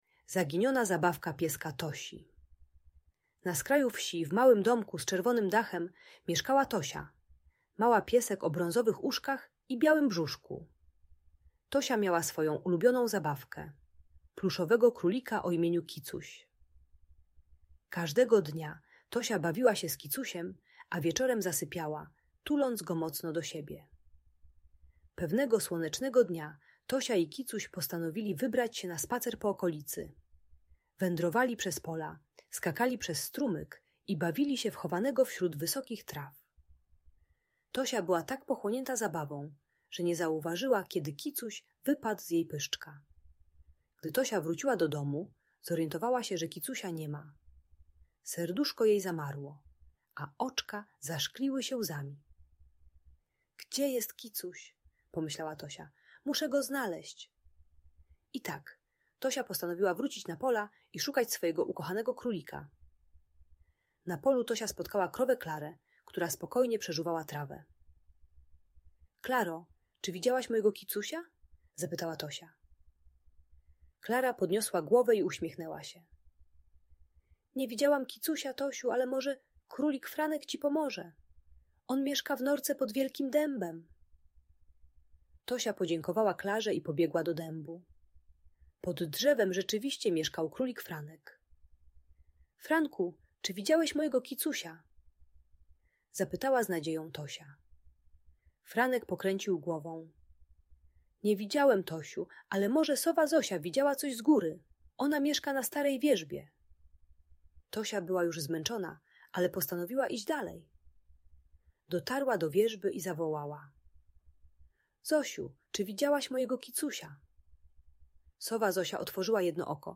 Zaginiona Zabawka Pieska Tosi - Audiobajka